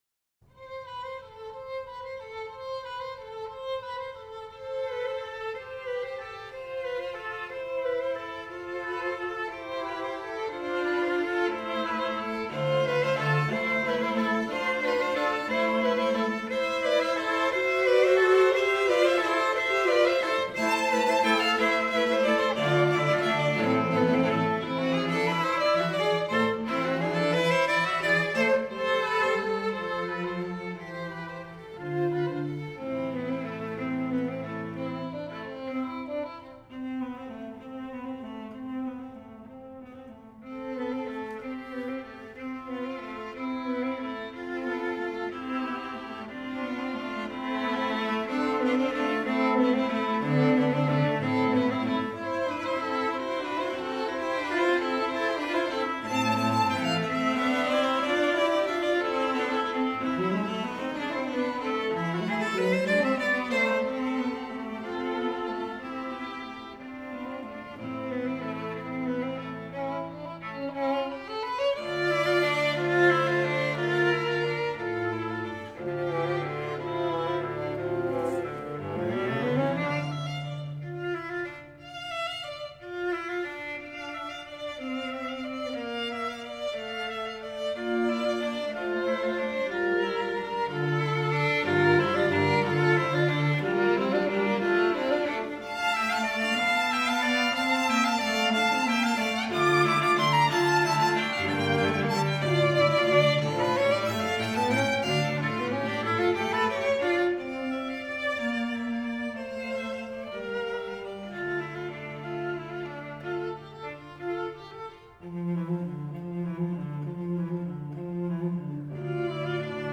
Voicing: String Trio